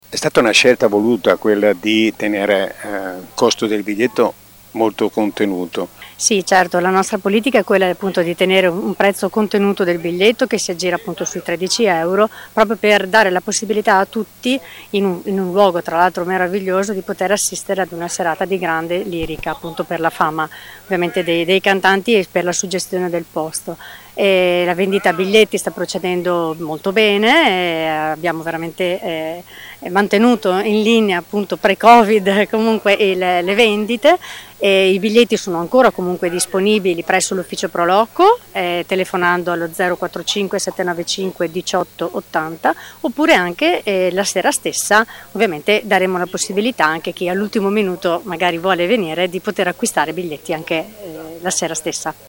Le interviste del nostro corrispondente